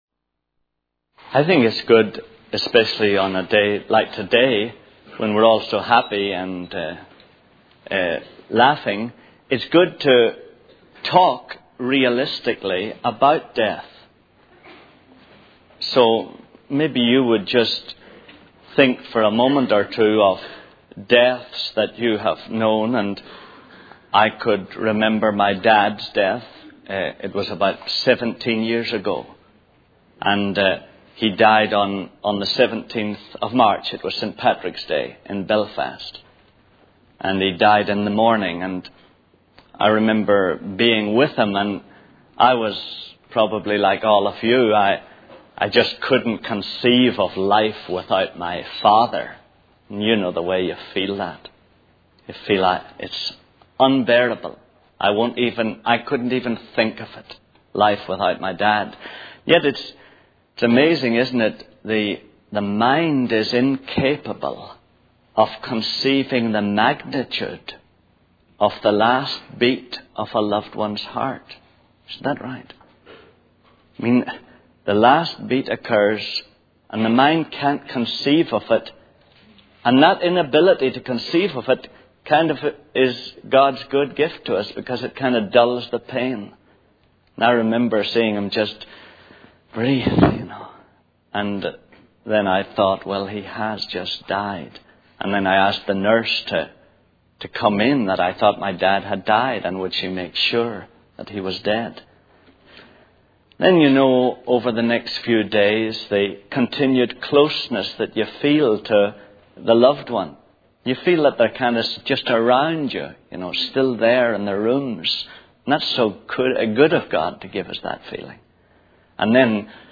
In this sermon, the speaker discusses the importance of establishing historical facts, particularly in relation to the resurrection of Jesus Christ. He mentions four rules that historians and philosophers use to determine the validity of a matter of fact.